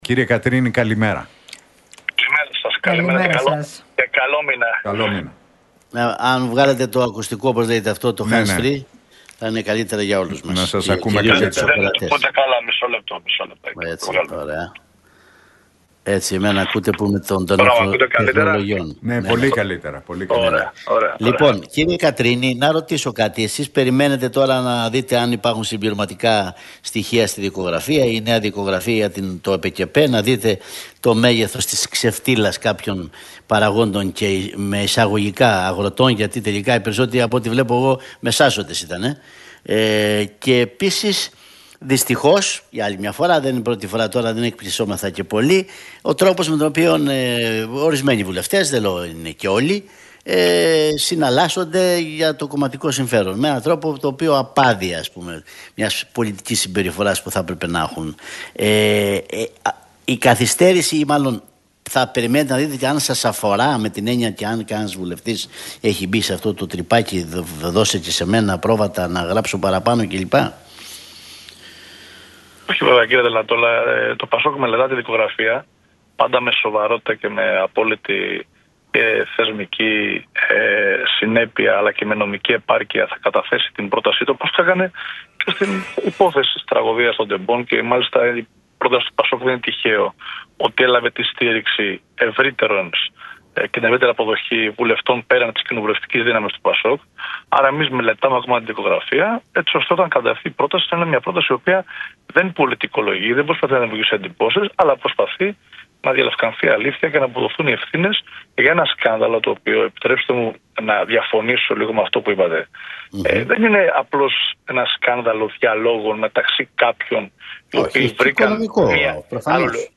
Κατρίνης στον Realfm 97,8 για ΟΠΕΚΕΠΕ: Οι πρώτες ενδείξεις δείχνουν ότι μάλλον οδηγούμαστε σε Προανακριτική Επιτροπή για Βορίδη - Αυγενάκη